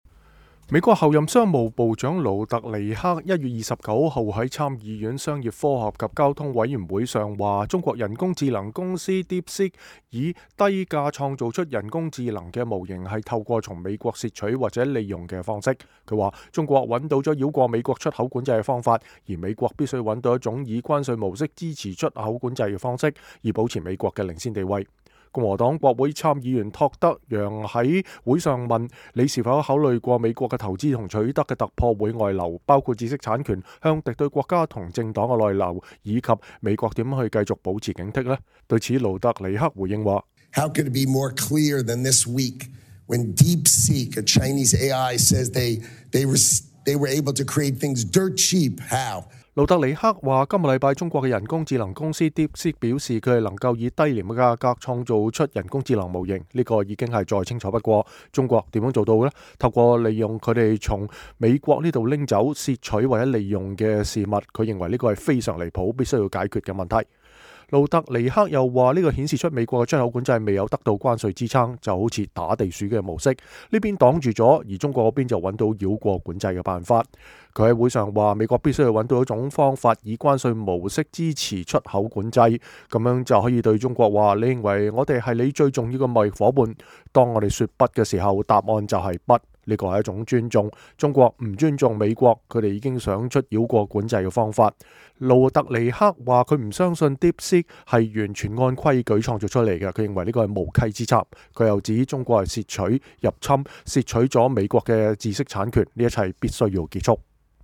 美國候任商務部長霍華德·盧特尼克(Howard Lutnick)1月29日在參議院商業、科學和交通委員會上表示，中國人工智能公司DeepSeek以低價創造出人工智能模型，是透過從美國竊取或利用的方式。他說，中國找到了繞過美國出口管制的方法，而美國必須找到一種以關稅模式支持出口管制的方式，以保持美國的領先地位。